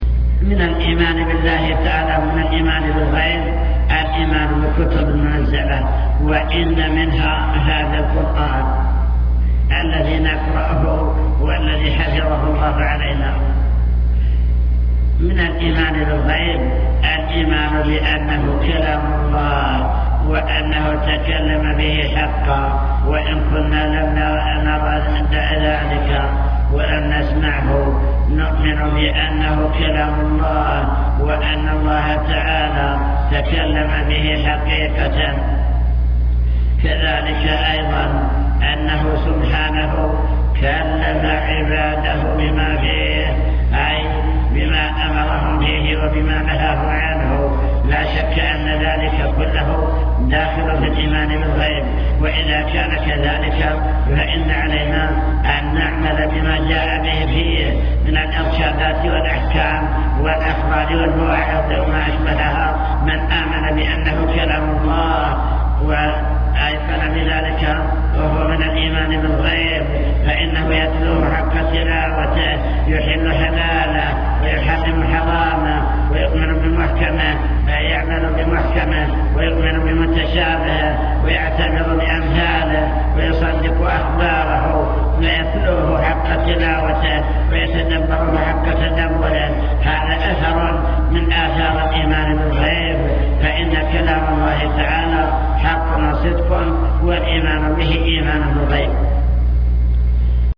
المكتبة الصوتية  تسجيلات - محاضرات ودروس  محاضرة الإيمان باليوم الآخر أمور غيبية يجب الإيمان بها